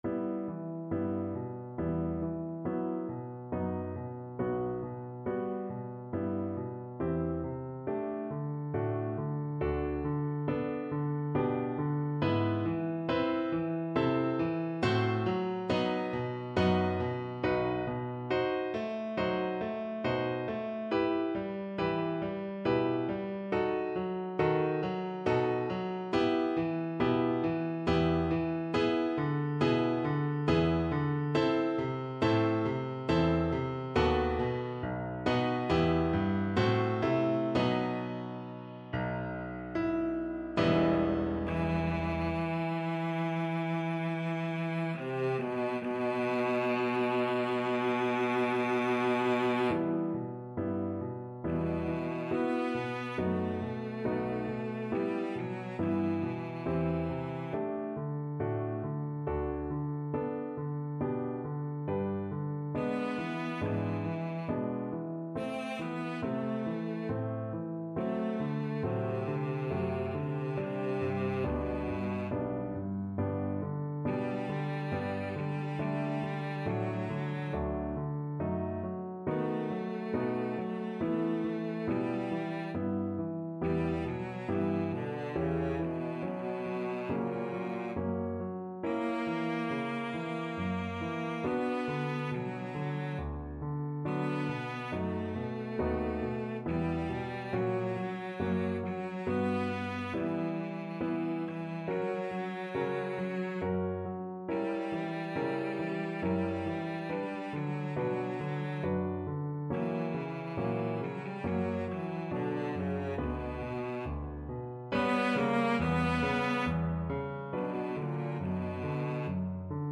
Classical Handel, George Frideric Ah! mio cor, schernito sei from Alcina Cello version
Cello
E minor (Sounding Pitch) (View more E minor Music for Cello )
Andante stretto (=c.69)
3/4 (View more 3/4 Music)
Classical (View more Classical Cello Music)